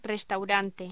Locución: Restaurante
locución
Sonidos: Voz humana